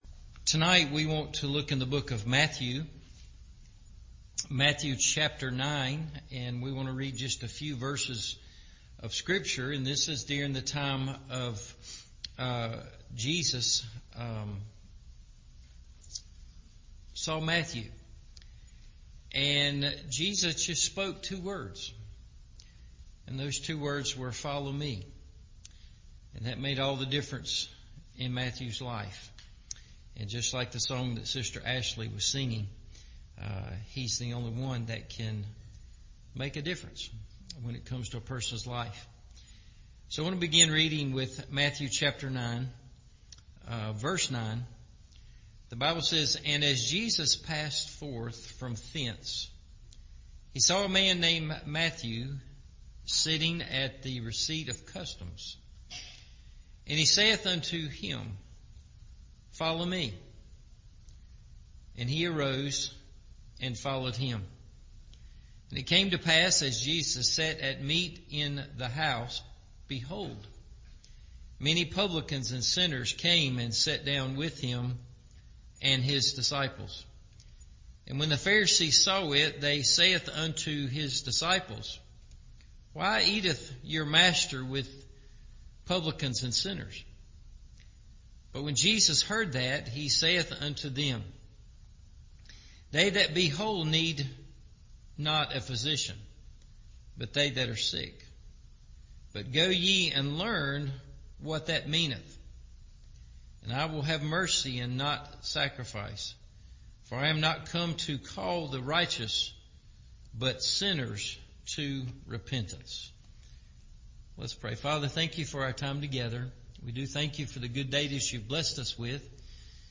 Follower of Jesus – Evening Service